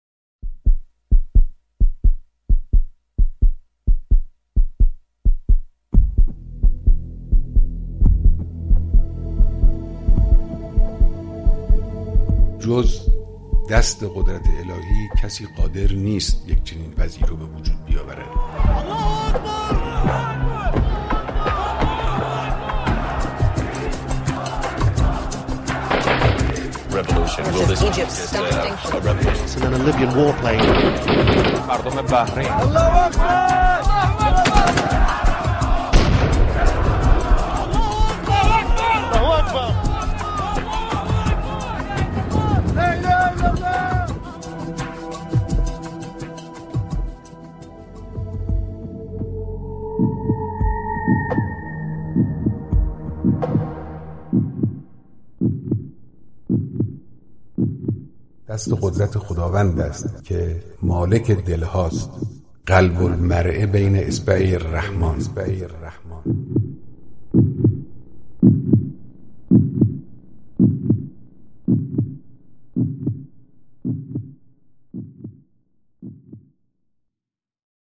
مقتطفات من كلمة الإمام الخامنئي في لقاء مع جمع من الرعيل الأول لقادة ومجاهدي «الدفاع المقدس»
ہفتۂ مقدس دفاع کے موقع پر مقدس دفاع کے کمانڈروں اور سینیئر سپاہیوں سے ملاقات میں تقریر